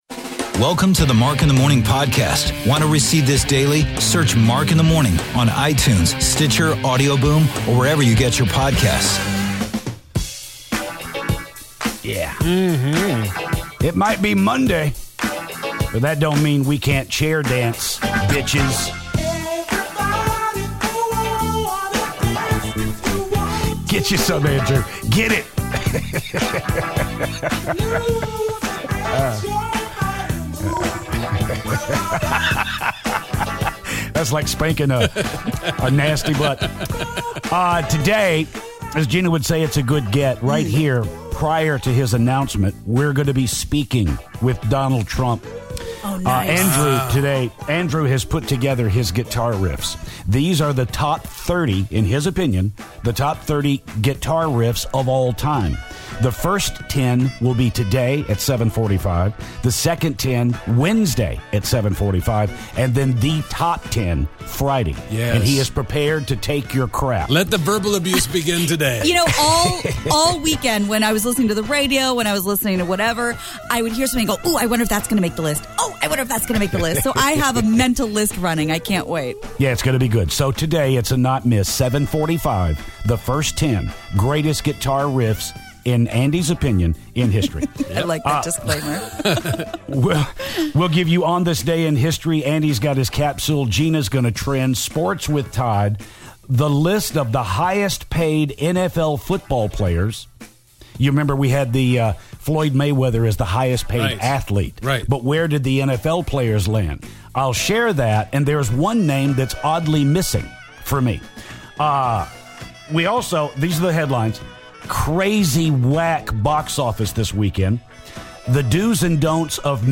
guitar riff countdown begins, Donald Trump calls the show and the guys recap the weekend!